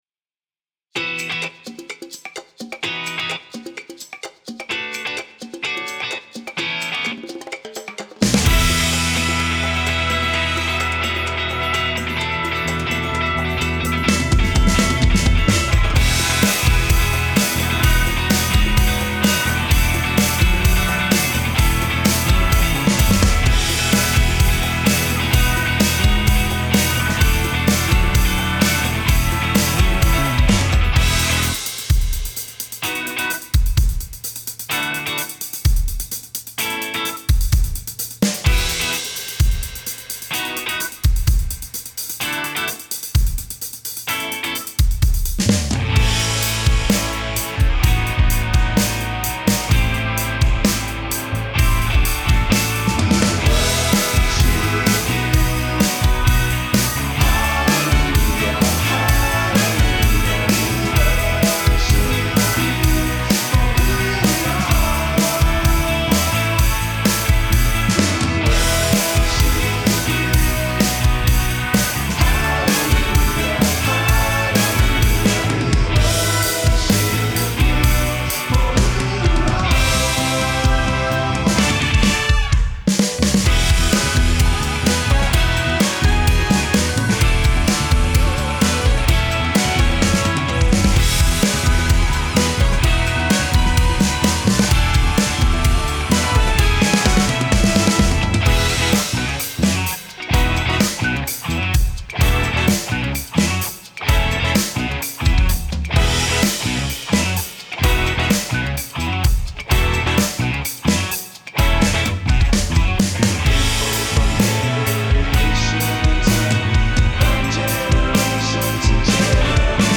Key: E BPM: 128 Time sig: 4/4 Duration:  Size: 13MB
Contemporary Funk Gospel Pop Rock Soul Worship